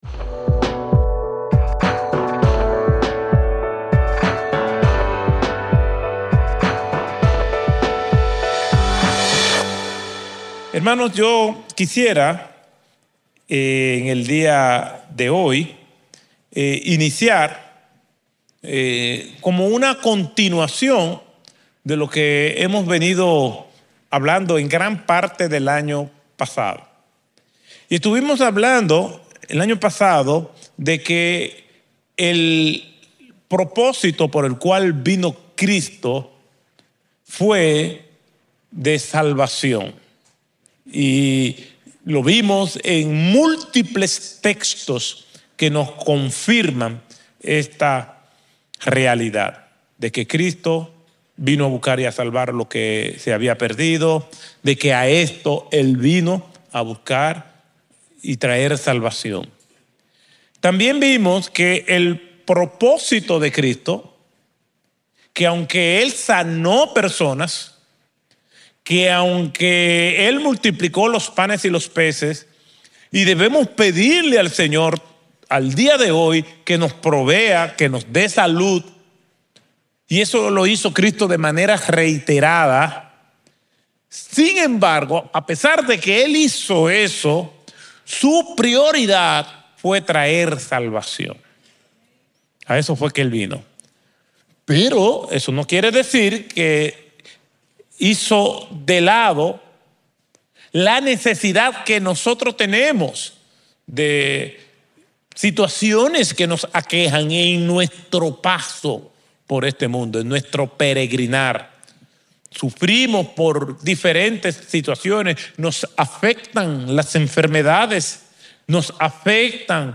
Un mensaje de la serie "Ser para hacer."